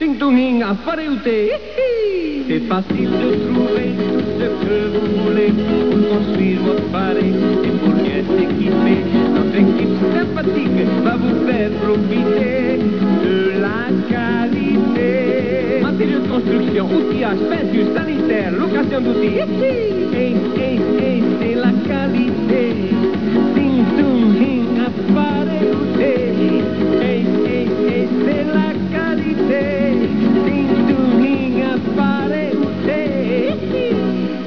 Publicités radio
qui signe cette publicité sur un air de hukulele.